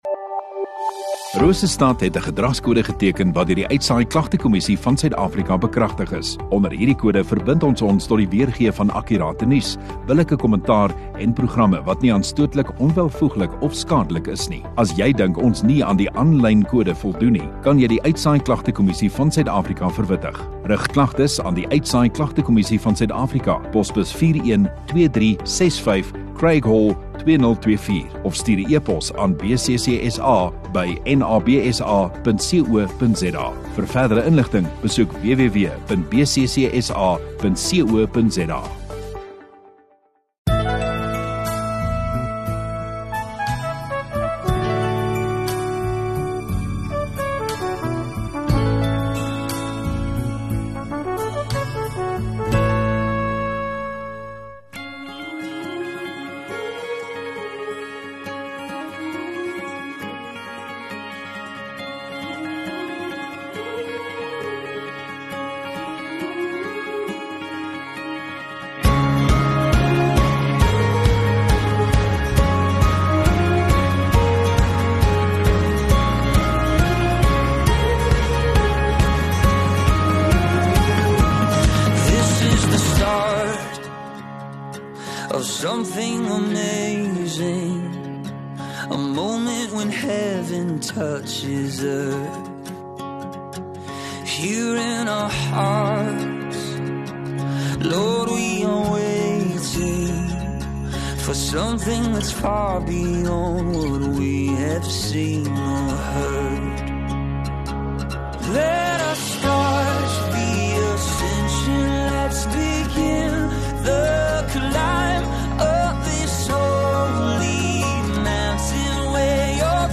9 Feb Sondagoggend Erediens